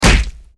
Punch10.wav